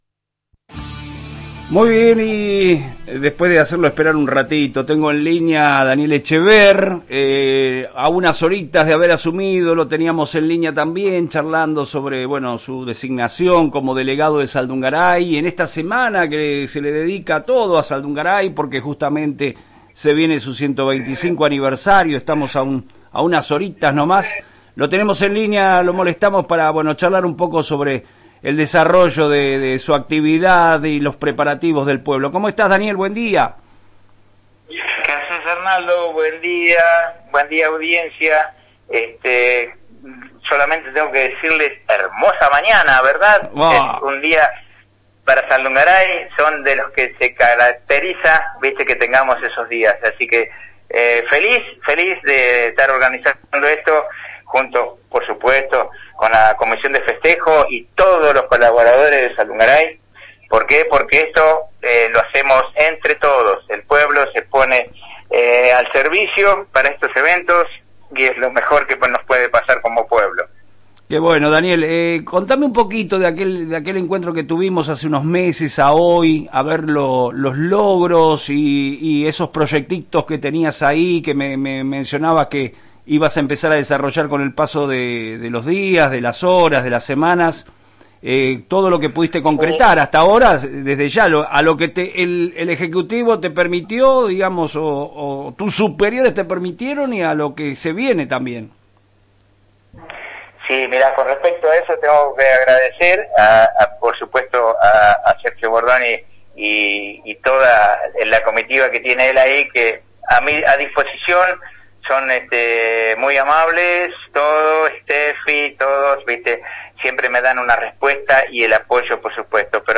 El Delegado de Saldungaray agradece el apoyo del intendente Sergio Bordoni y destaca la labor de la planta municipal y la comunidad en la organización de la fiesta aniversario